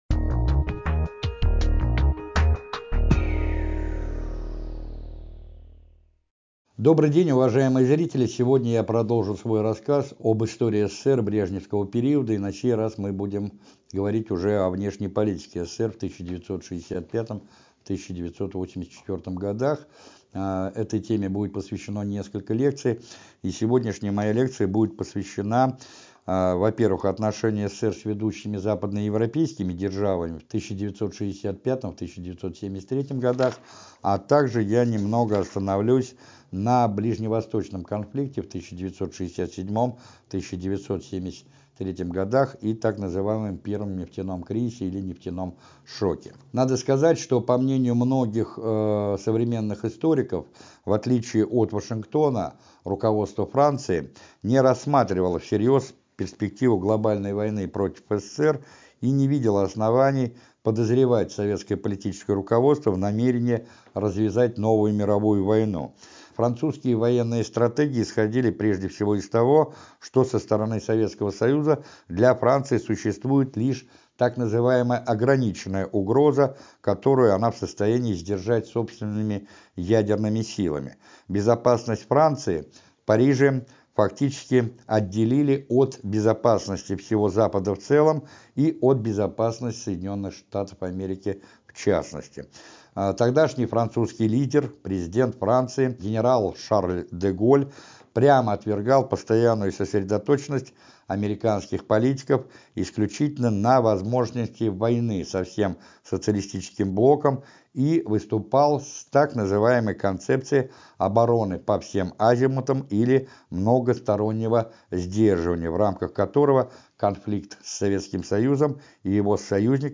Сто сороковая лекция из цикла «История России» посвящена европейской и ближневосточной политике СССР в 1965–1973 гг.